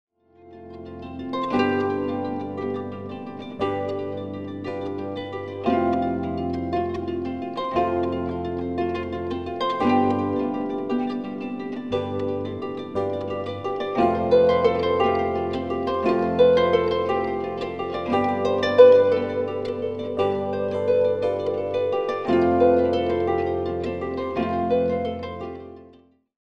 Featuring the harp, piano & guitar
Recorded at Healesville Sanctuary